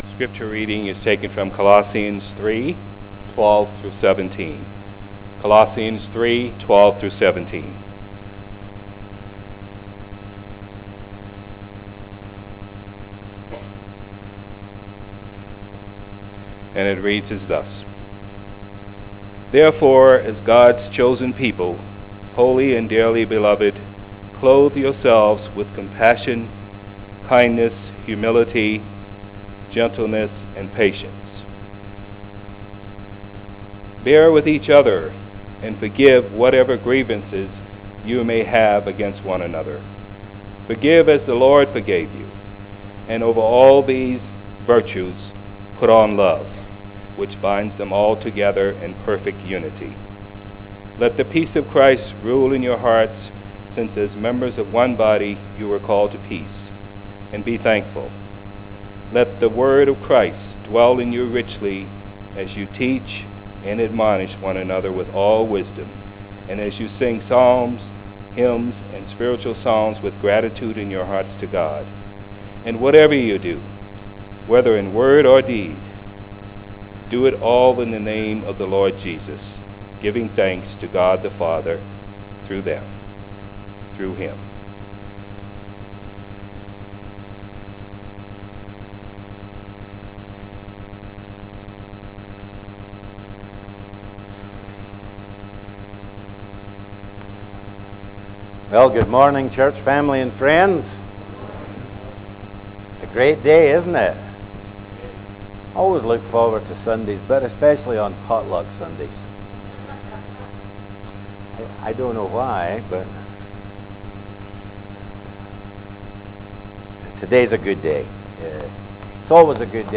from → Classes / Sermons / Readings, Sermon